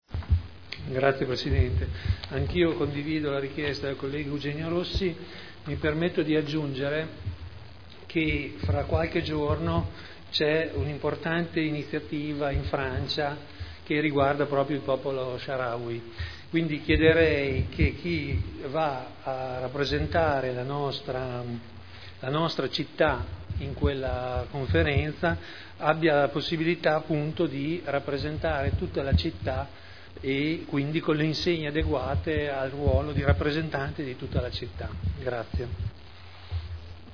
Seduta del 25/10/2010. Dibattito su richiesta di Eugenia Rossi sull'osservanza di un minuto di silenzio per la morte di un ragazzino di 16 anni della città di El Aium, popolo Saharawi, gemellata con la città di Modena
Audio Consiglio Comunale